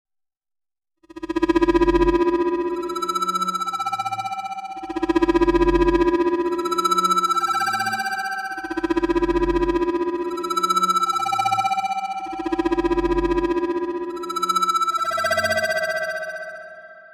Some more random generated drones, is going to pair very well with Lyra 8